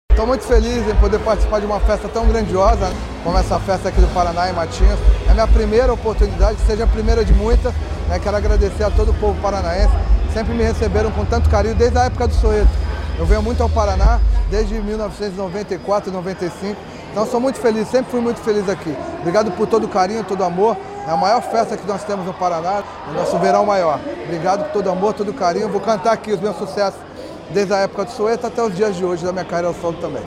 Sonora do cantor Belo sobre o show no Verão Maior Paraná